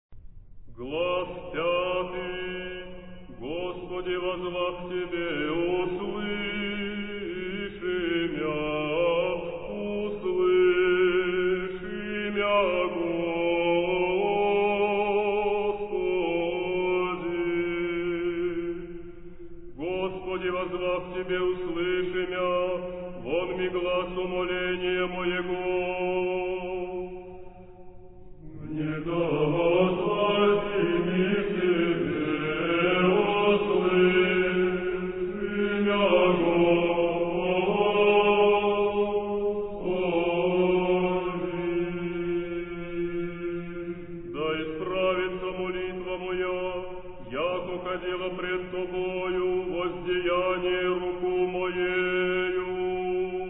ロシアの古い聖歌ズナメニイ　晩課　「主や爾に?(よ)ぶ」　ユニゾン